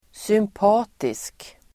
Uttal: [symp'a:tisk]